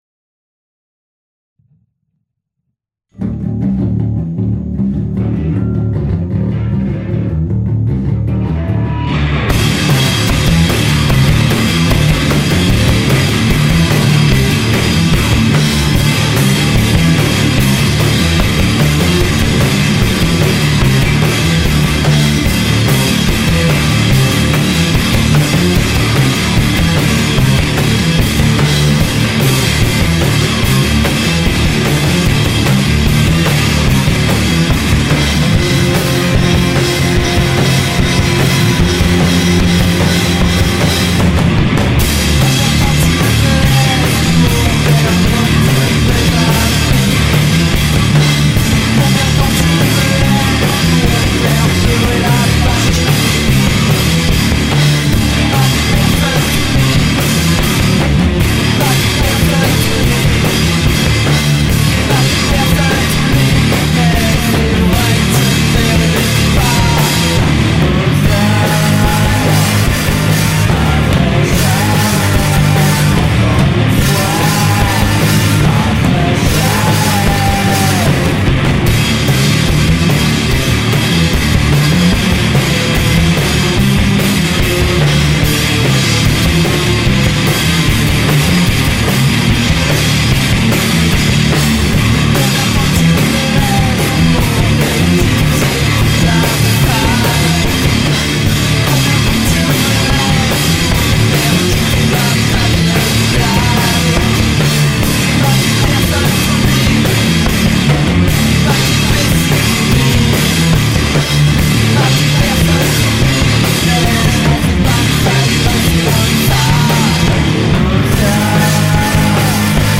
rock stoner lo-fi
batterie